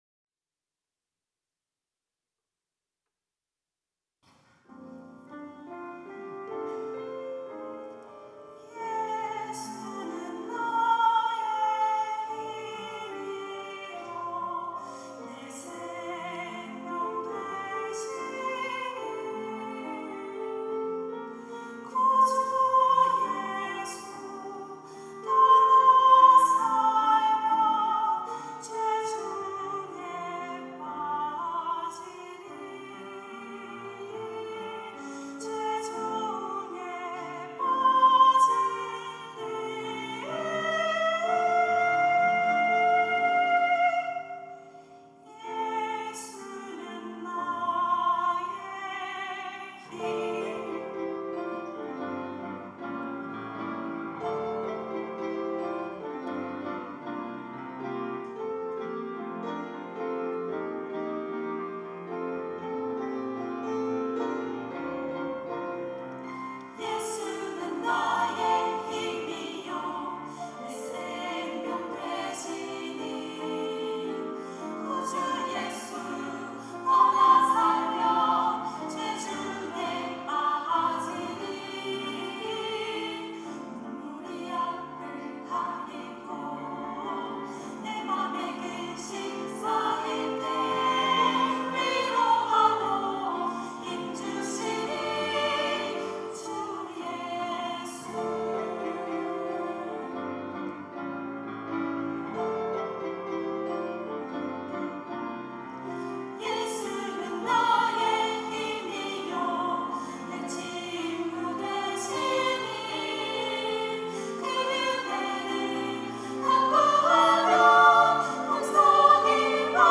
찬양대 글로리아